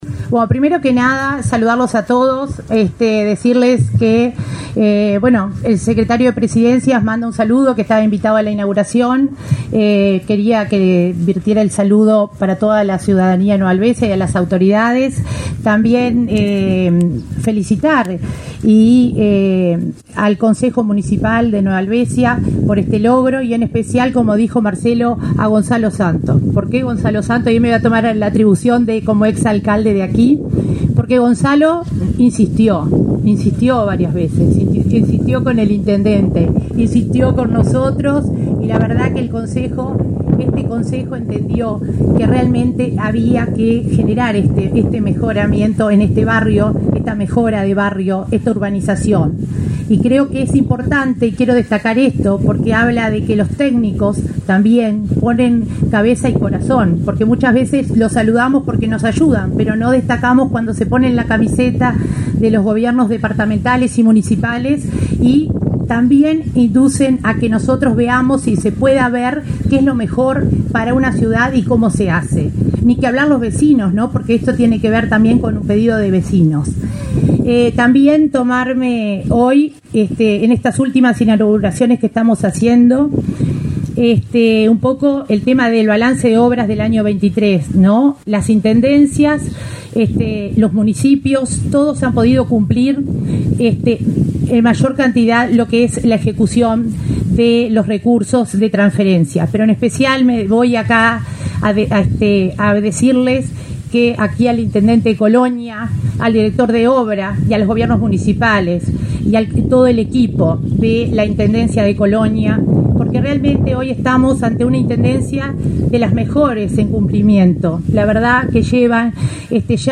Palabras de la coordinadora de Descentralización de la OPP, María de Lima
Este 22 de diciembre se realizó la inauguración de obras de pavimentación de calles en la ciudad de Nueva Helvecia, departamento de Colonia.